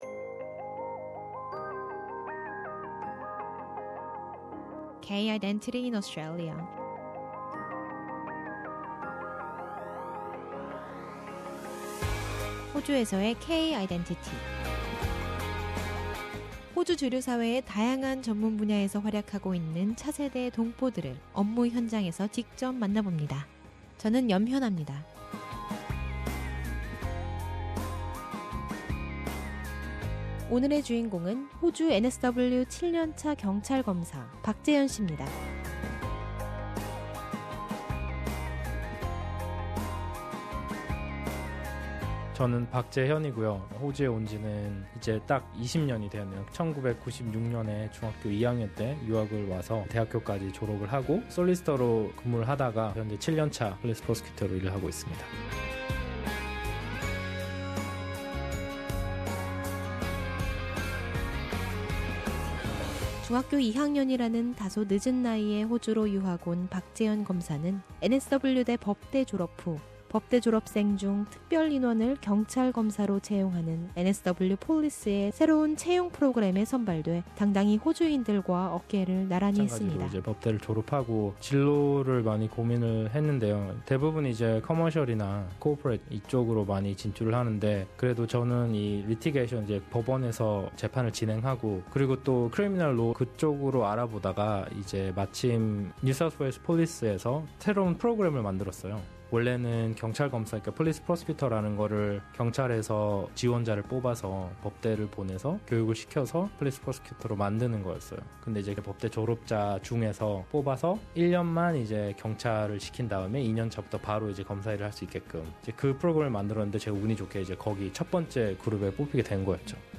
K-Identity explores the identity issues through an interview with a variety of second-generation Korean Australian professionals working in various fields of the Australian mainstream.